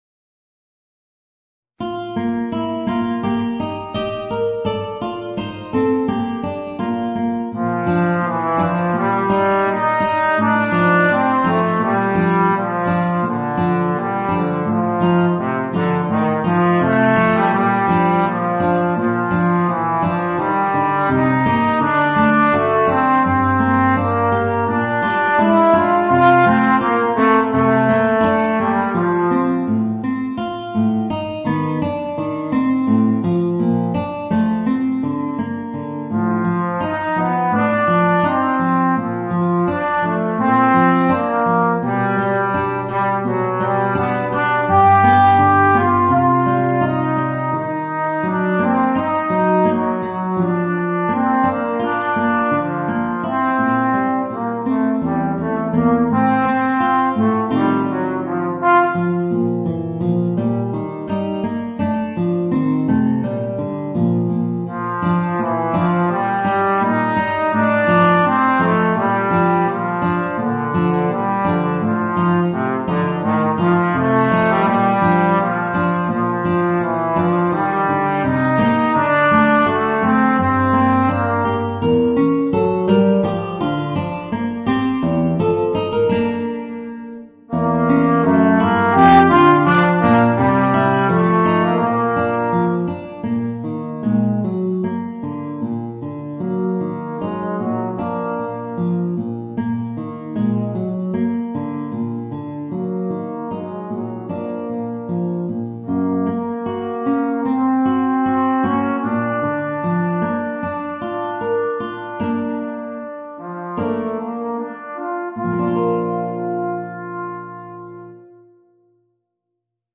Voicing: Guitar and Trombone